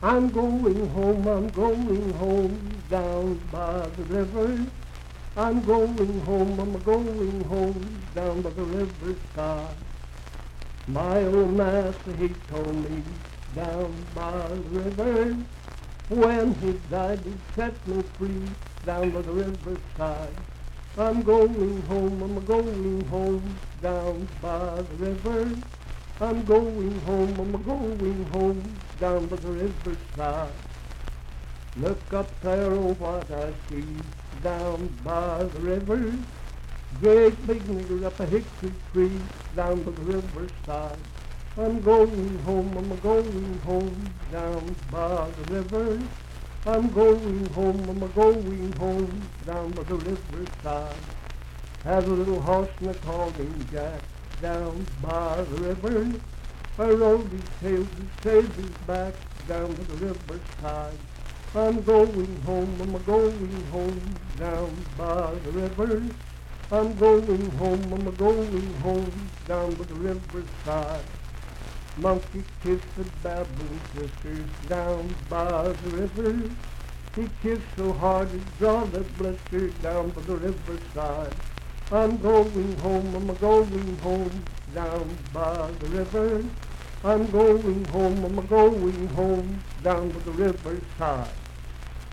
Unaccompanied vocal music
Minstrel, Blackface, and African-American Songs
Voice (sung)